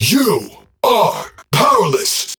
If you are defending a site and you hear the War Machine shout, "